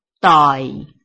臺灣客語拼音學習網-客語聽讀拼-南四縣腔-開尾韻
拼音查詢：【南四縣腔】doi ~請點選不同聲調拼音聽聽看!(例字漢字部分屬參考性質)